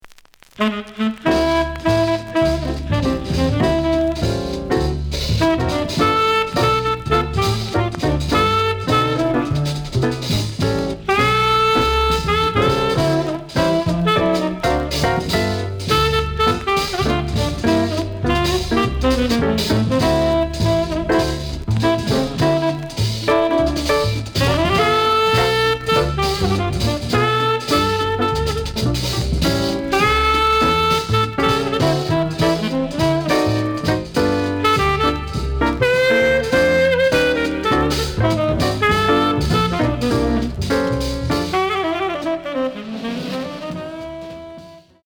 The audio sample is recorded from the actual item.
●Genre: Modern Jazz, Cool Jazz